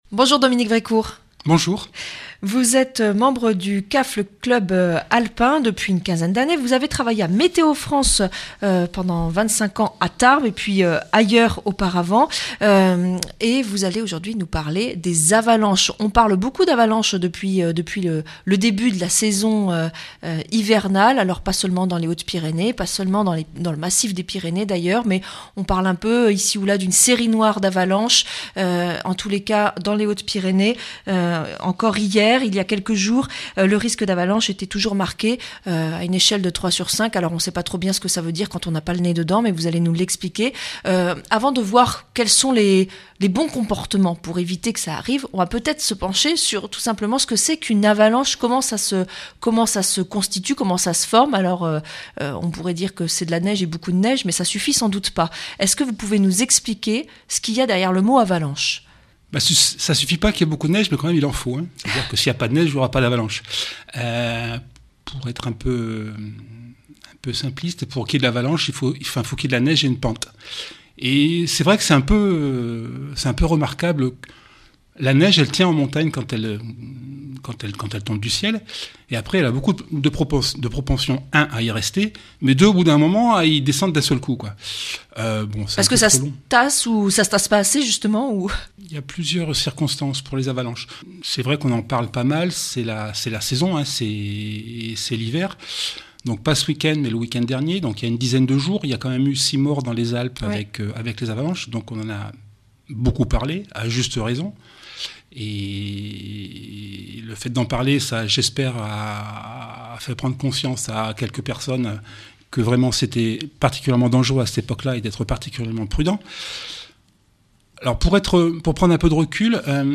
Interview et reportage du 22 janv.